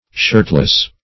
Search Result for " shirtless" : The Collaborative International Dictionary of English v.0.48: Shirtless \Shirt"less\, a. Not having or wearing a shirt.